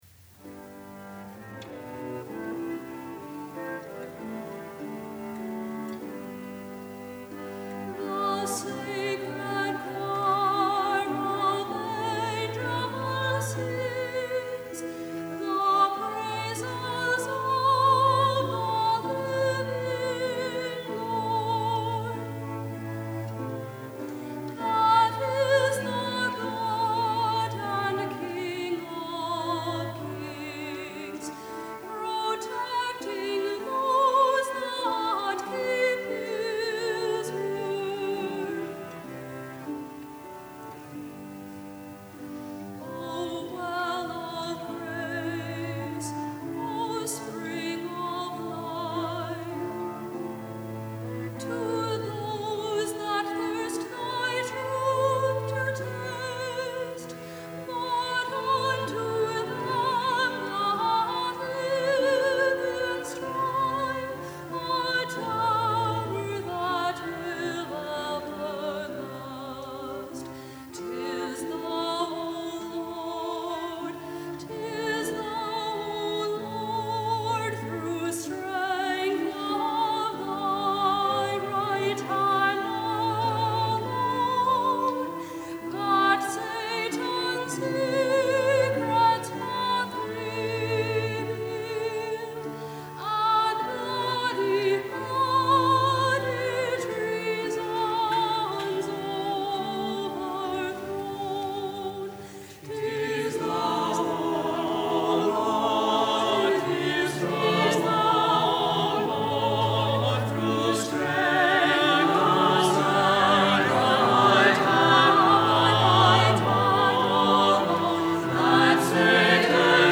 Consort song from ‘An Howres Recreation in Musicke’ (1606) . . .two verses solo with choral refrain.
soprano